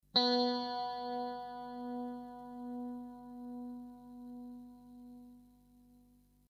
Einfach auf den Link für die jeweilige Gitarrensaite klicken und nach dem Ton stimmen
H Saite